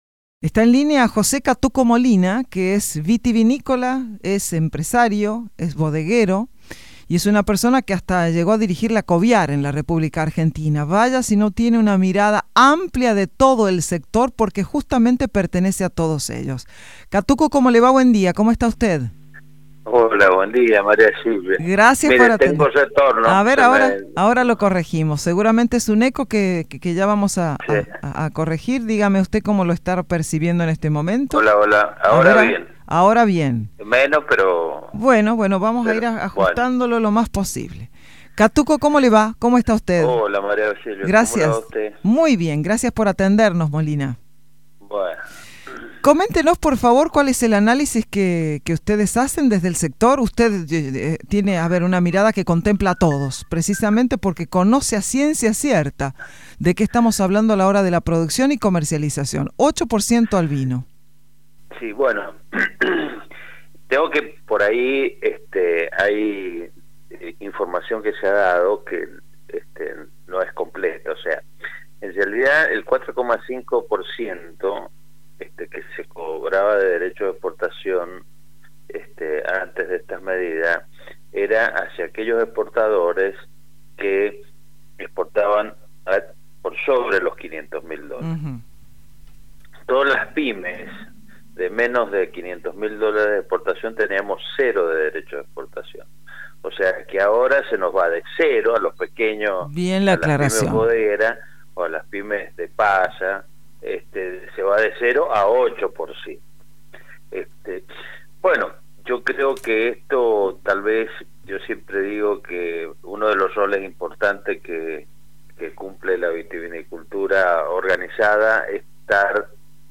sostuvo ante los micrófonos de Radio Sarmiento que «en la vitivinicultura